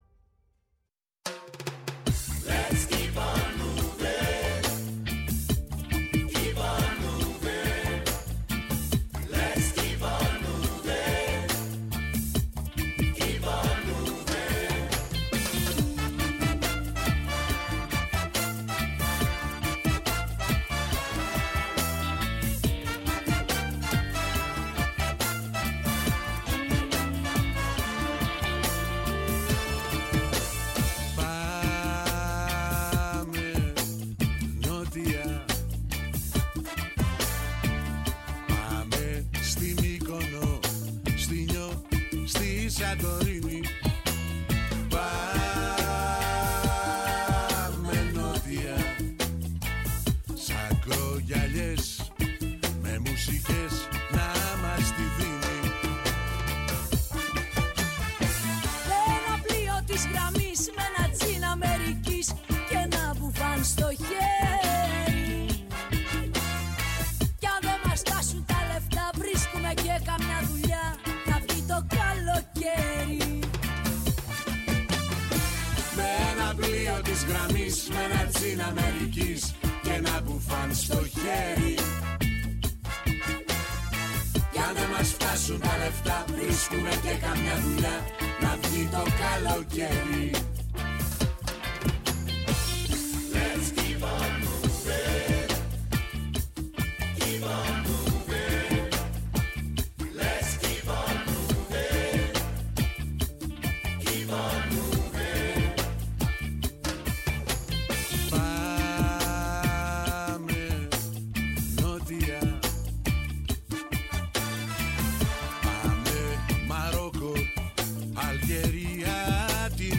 Η ΦΩΝΗ ΤΗΣ ΕΛΛΑΔΑΣ Αποτυπωμα Πολιτισμός Συνεντεύξεις